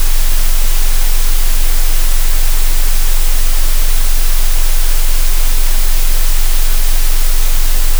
1. Синусоїда 22 Гц — основна теплова сигнатура вагань
2. Амплітудна модуляція — збільшується з невизначеністю (γ×0,6)
3. Фазовий джитер — «боротьба» — внутрішній стан системи не стабільний
4. Гармонійний шум — ефект Баркгаузена, масштабований на γ («зернистість» нерішучості)
5. Тепловий шум — фізичне тепло прийняття рішень, зроблене чутним
Результат — не музика. Це діагностика.